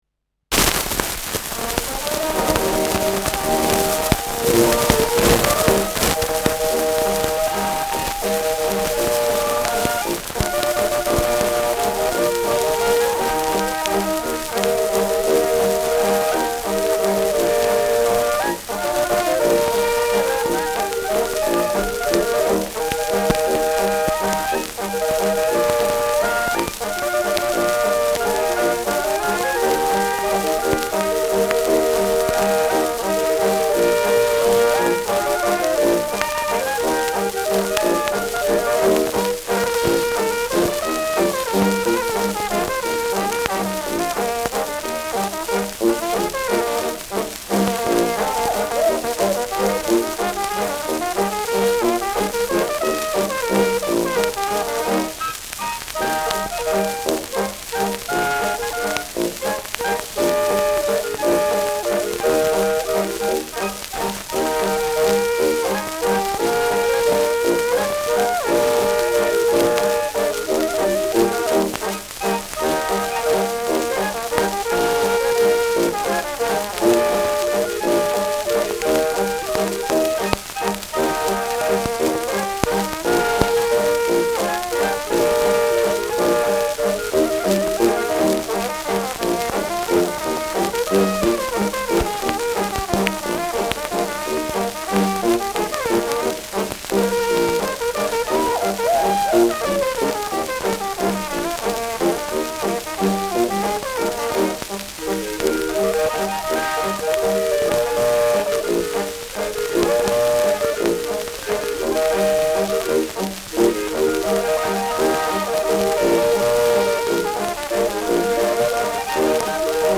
Schellackplatte
starkes Rauschen : abgespielt : leiert : durchgehend Knacken : gelegentliches „Schnarren“
Dachauer Bauernkapelle (Interpretation)
Mit Juchzern.